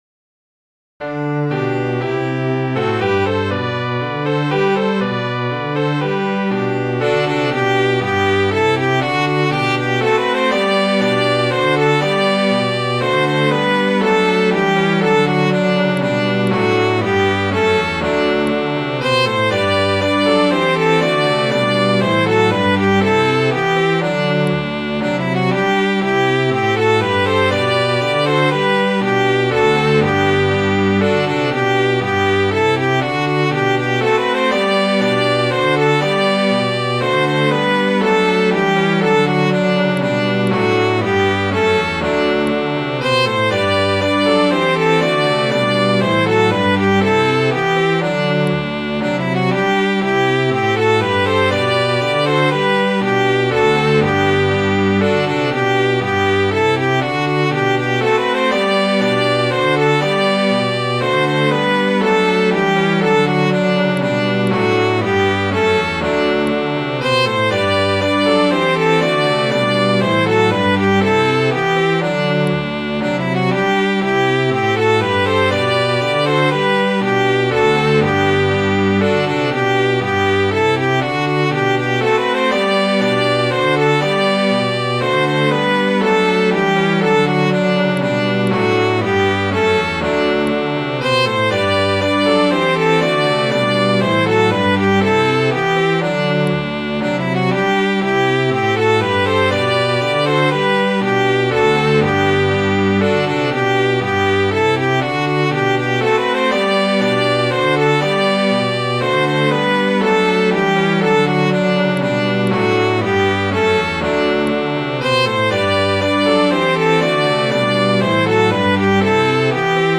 Midi File, Lyrics and Information to The Bold Princess Royal
The song is sung to several tunes, this is an arrangement by Vaughan Williams.